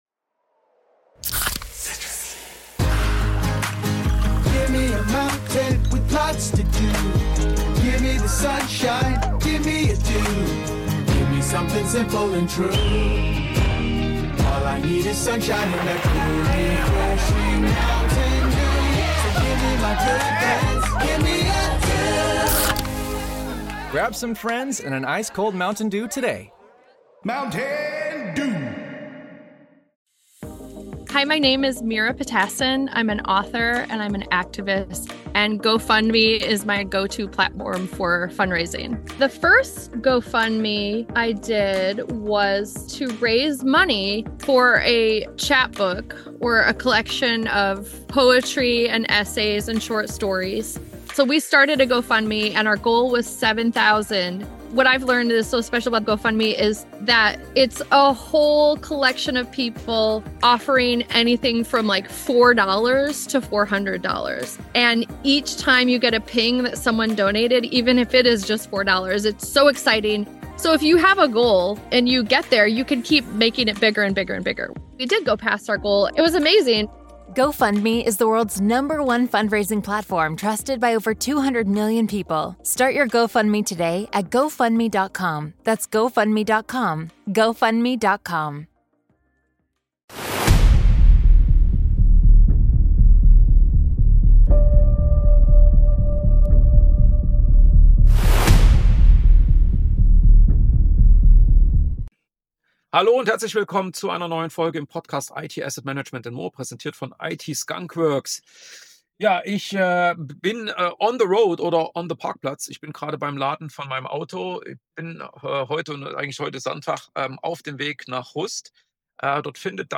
Beschreibung vor 1 Tag In dieser On-the-Road-Episode  wird’s strategisch, ehrlich und ziemlich praxisnah: Zwischen Ladestopp, Parkplatz und der Fahrt Richtung CloudFest in Rust geht es um eine Frage, die in vielen Unternehmen viel zu schnell beantwortet wird und genau deshalb oft falsch: Wo sollte Innovation eigentlich wirklich ansetzen?